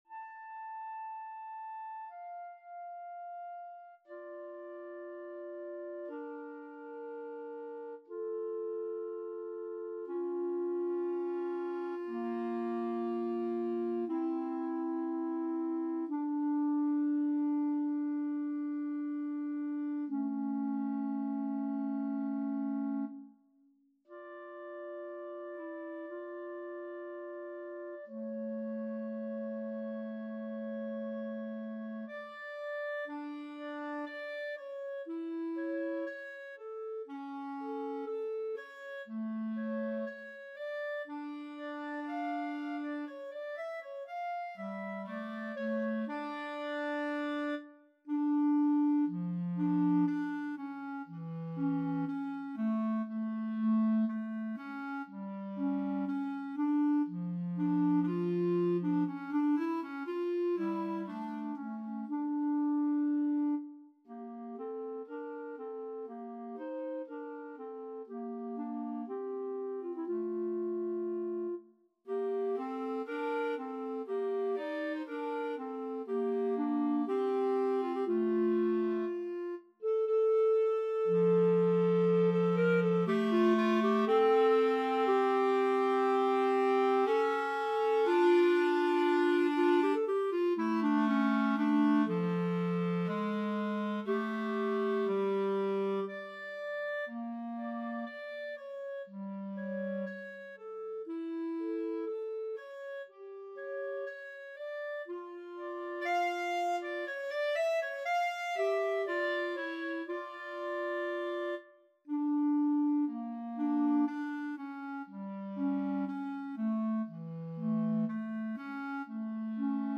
Clarinet 1Clarinet 2
4/4 (View more 4/4 Music)
Adagio non troppo